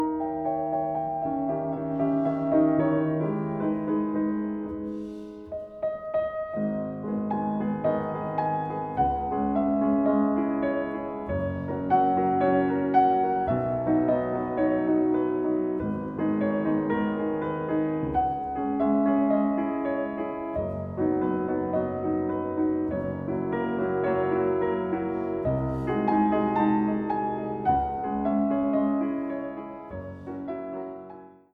Allegretto